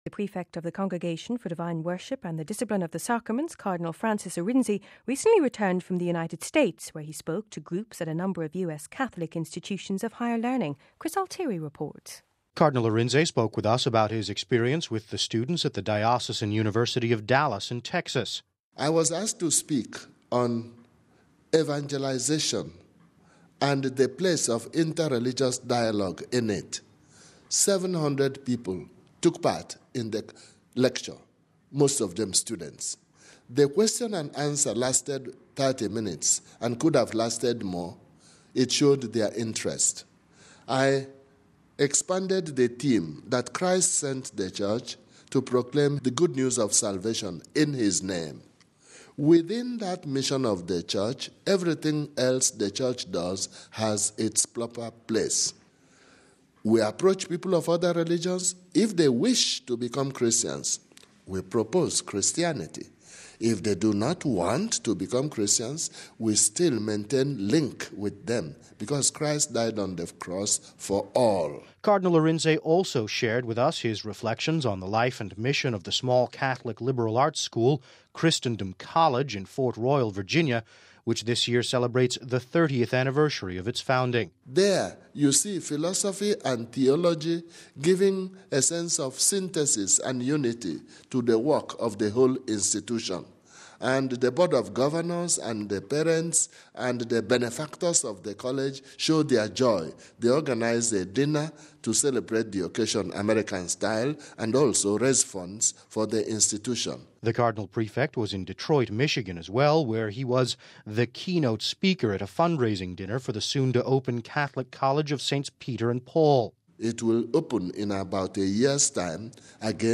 reports…